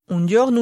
[un ɟɔrnu]